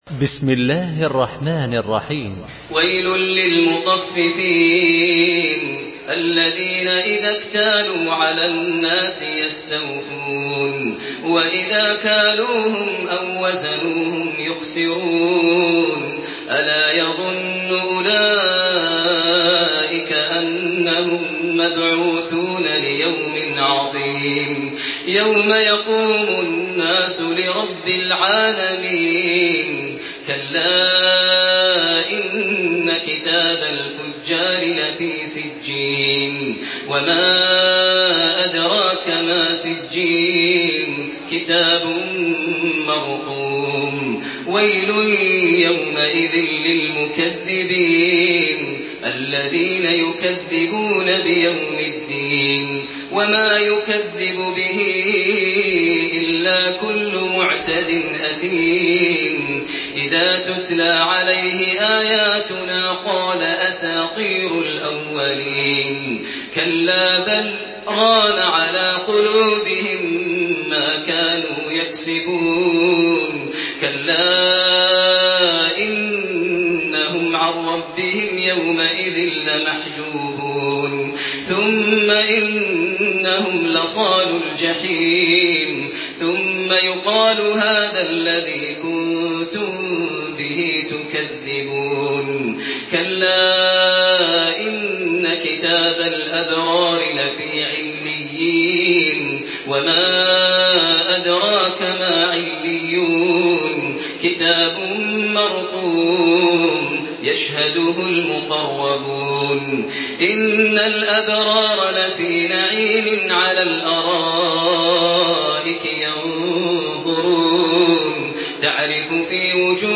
سورة المطففين MP3 بصوت تراويح الحرم المكي 1428 برواية حفص عن عاصم، استمع وحمّل التلاوة كاملة بصيغة MP3 عبر روابط مباشرة وسريعة على الجوال، مع إمكانية التحميل بجودات متعددة.
تحميل سورة المطففين بصوت تراويح الحرم المكي 1428